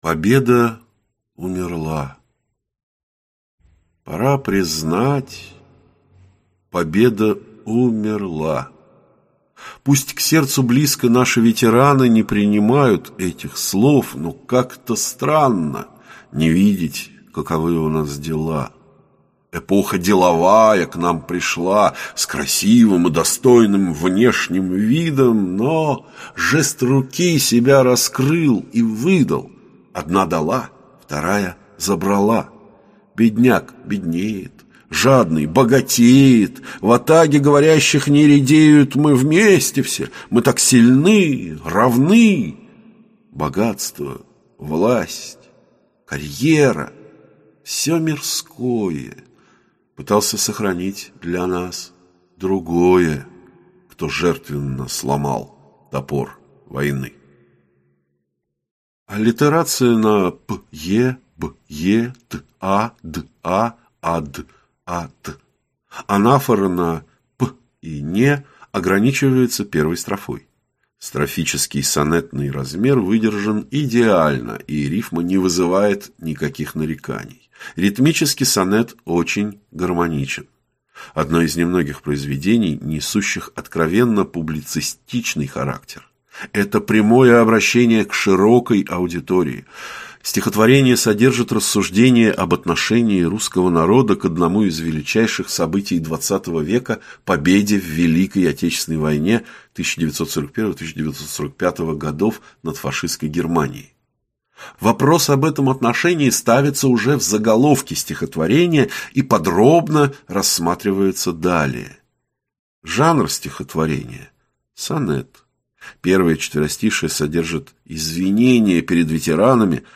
Аудиокнига Литературоведческий анализ стихотворений | Библиотека аудиокниг